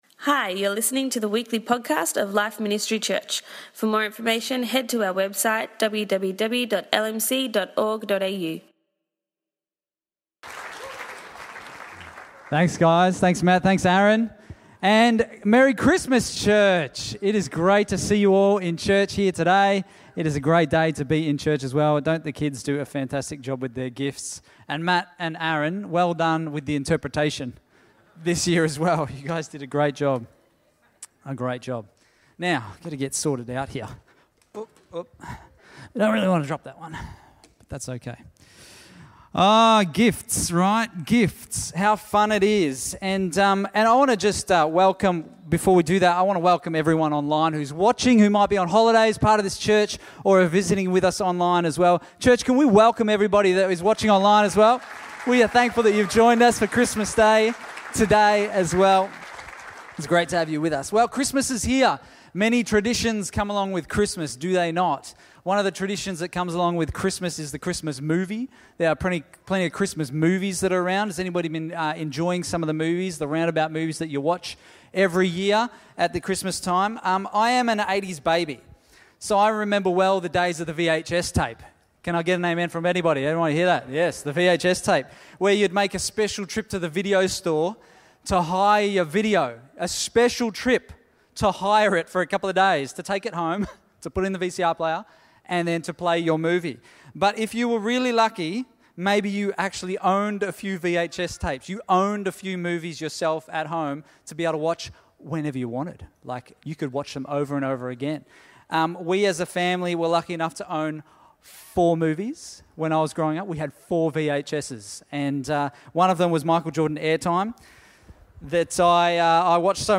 Christmas Day message